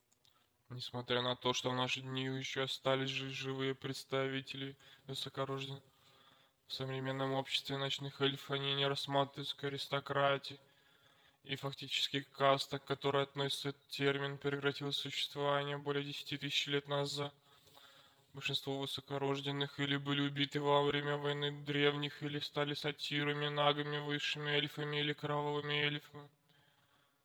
Варианты изменения голоса вы можете видеть на скриншоте.
Записал я звук в WAV для быстроты стандартными средствами Windows.
Arcane Guardian
arcaneguardian.wav